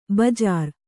♪ bajār